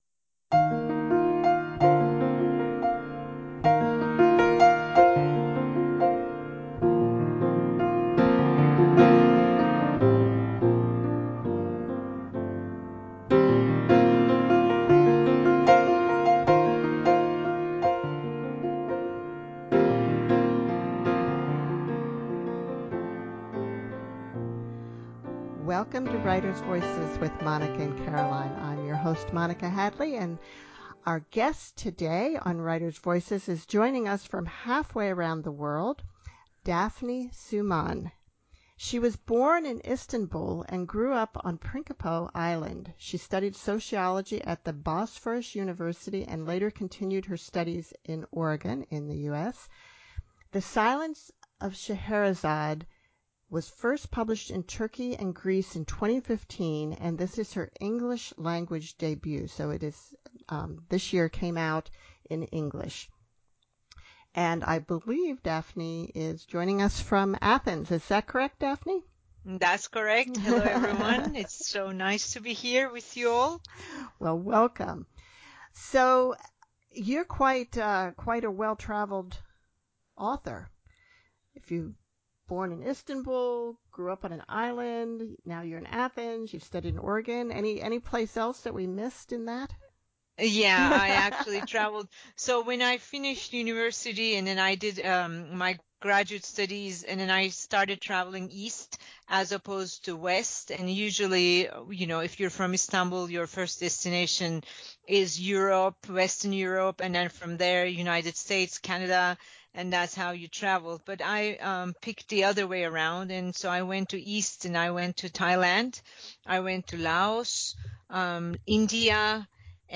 During the conversation